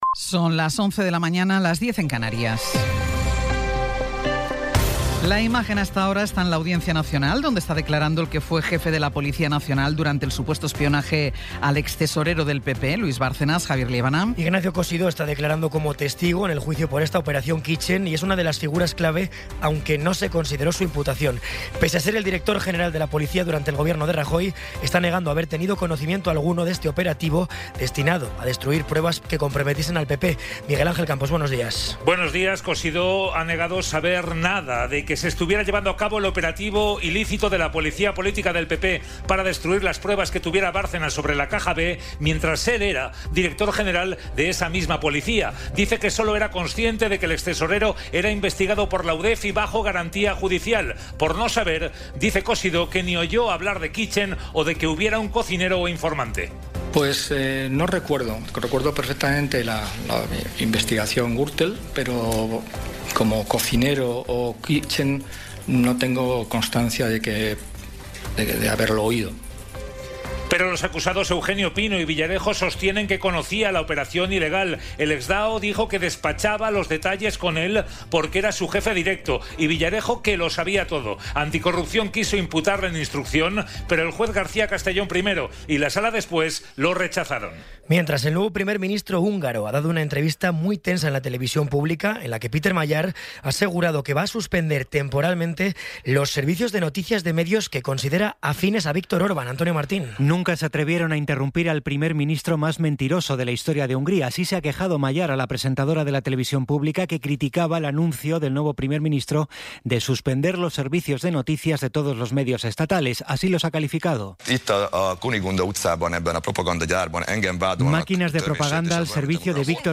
Resumen informativo con las noticias más destacadas del 15 de abril de 2026 a las once de la mañana.